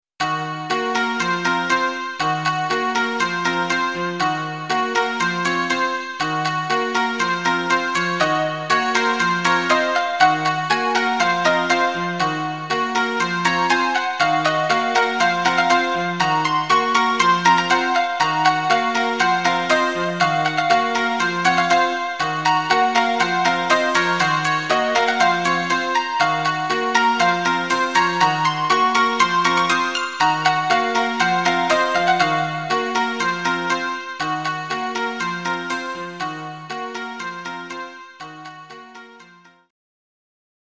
Japanese style music